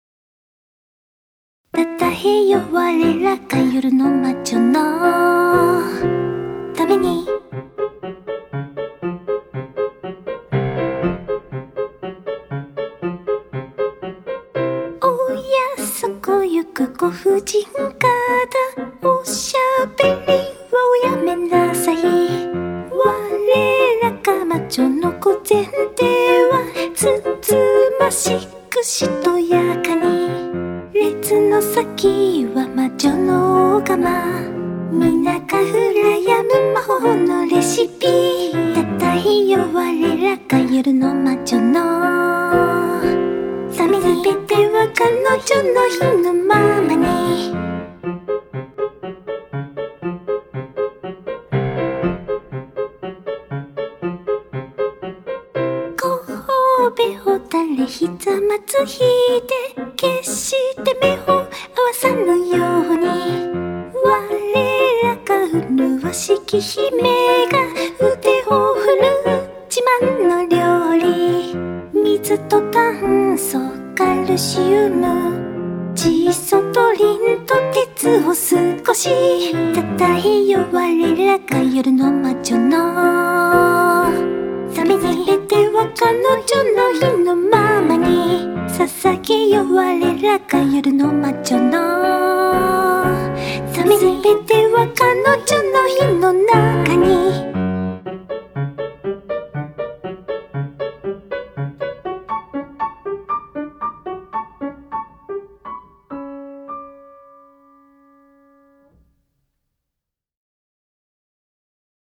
【Vocal / リマスター版2025】 mp3 DL ♪